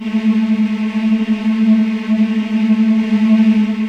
voiTTE64016voicesyn-A.wav